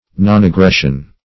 nonaggression.mp3